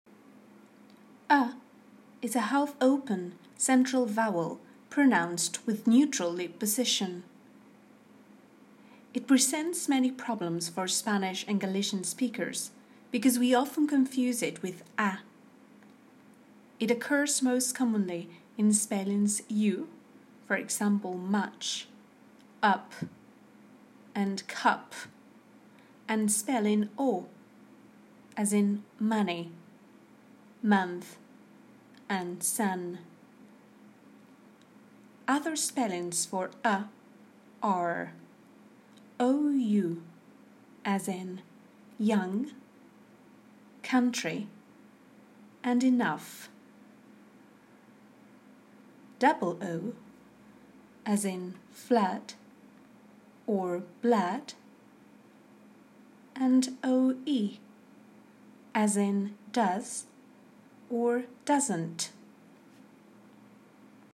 is a a half-open, central vowel pronounced with neutral lip position.